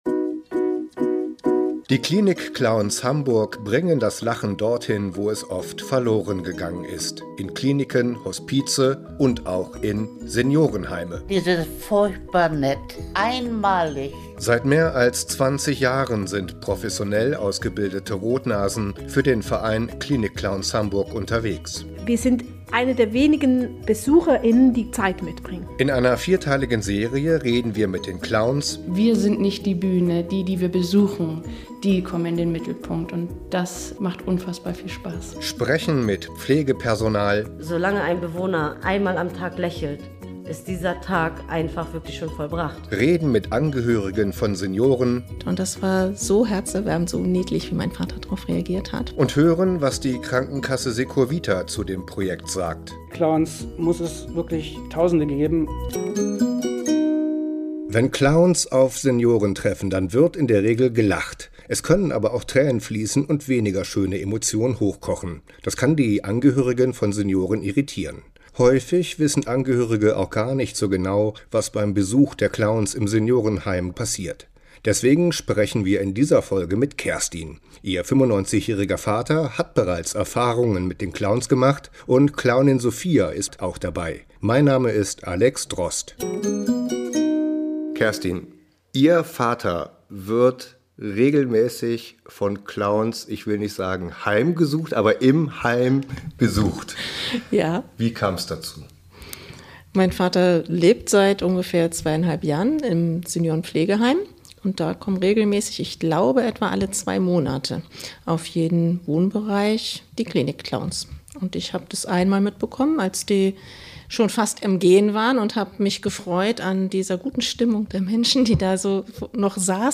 In der dritten Folge widmen wir uns den Angehörigen. Die Tochter eines besuchten Senioren teilt Erfahrungen und Eindrücke - persönliche Schilderungen, die eine Vorstellung davon schaffen, was Besuche von Seniorenclowns auszeichnet.